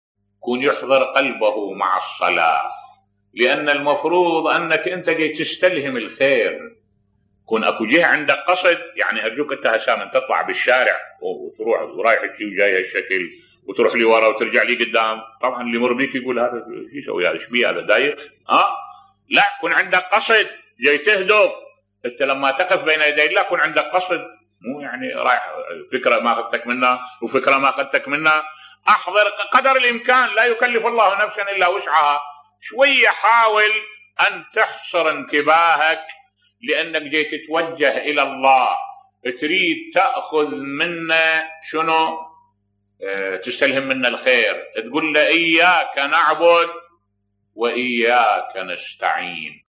ملف صوتی أهمية الحضور القلبي في الصلاة بصوت الشيخ الدكتور أحمد الوائلي